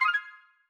confirm_style_4_003.wav